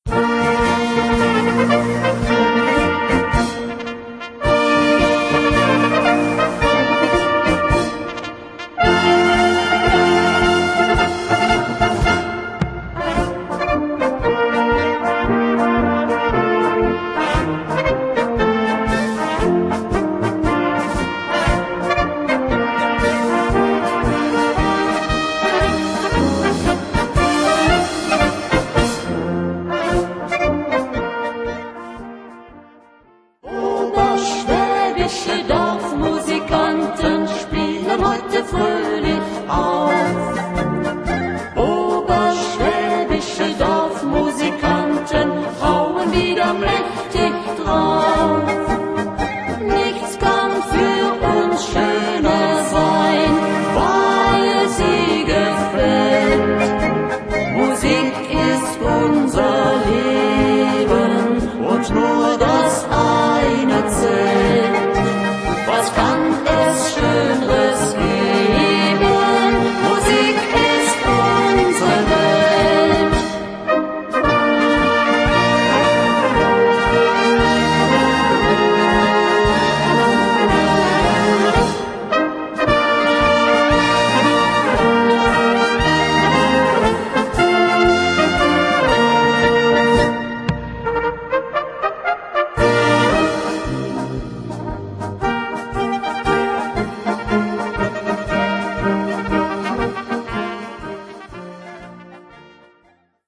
Gattung: Maschlied
Besetzung: Blasorchester